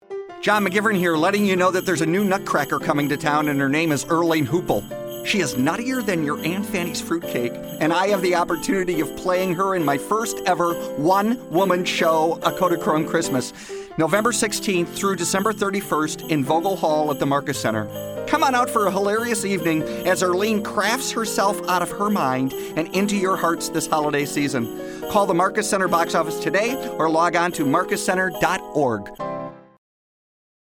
Kodachrome Christmas Radio Commercial